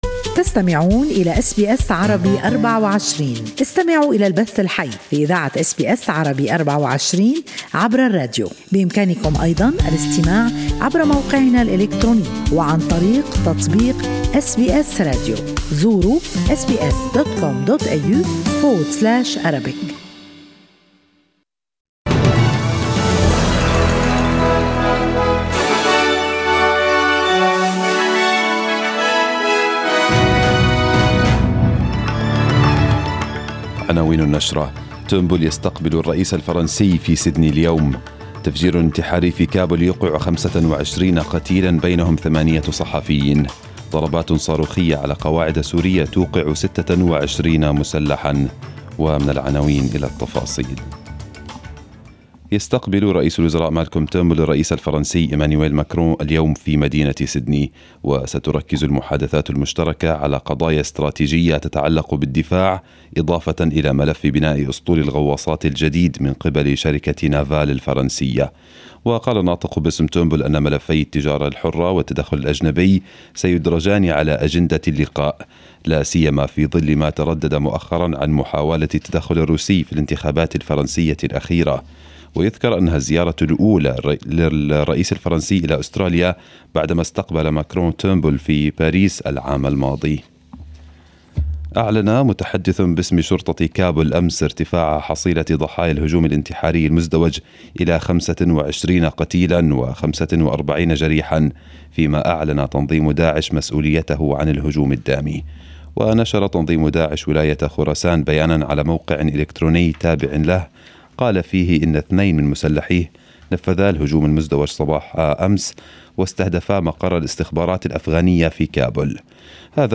Arabic News Bulletin 01/05/2018